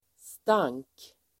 Uttal: [stang:k]